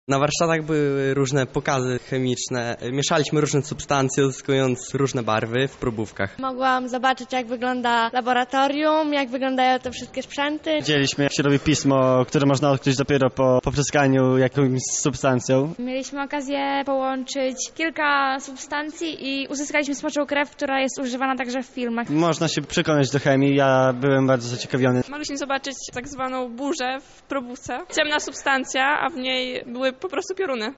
sonda-festiwal-nauki.mp3